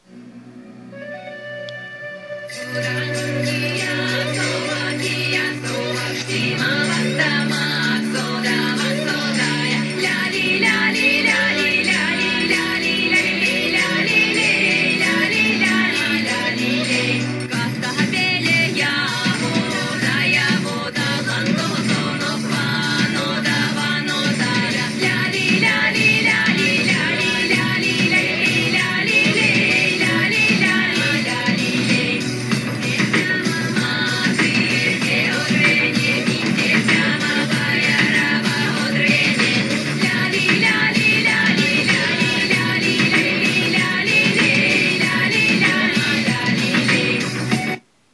Here’s a recording of a song in a mystery language.
the singing is very difficult to make out (and much of it consists of “da di da di…”, which could just about be any language). But there is a certain rhythmic quality that I associate with the Uralic languages.